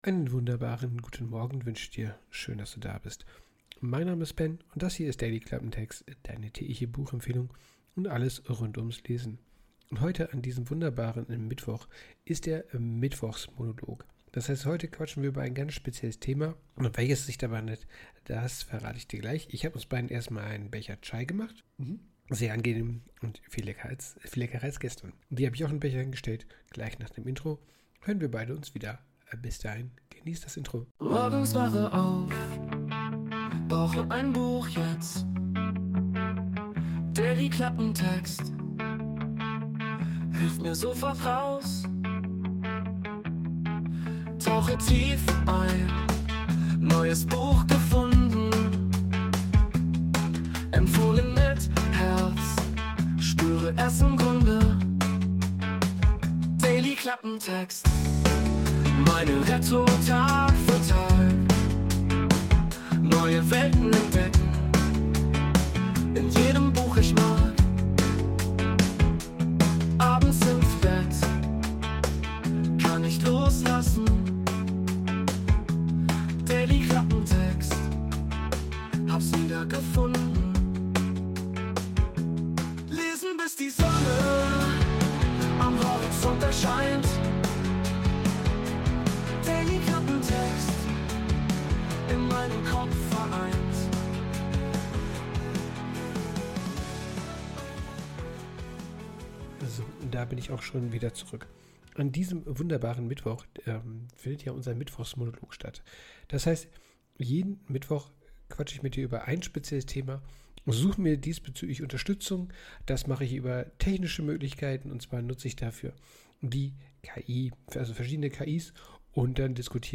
Für diesen Mittwochsmonolog habe ich wieder mit KI und Text2Speech experimentiert.
Wir lassen einen KI-generierten Text durch eine Software laufen und schauen wie daraus ein Gespräch entsteht.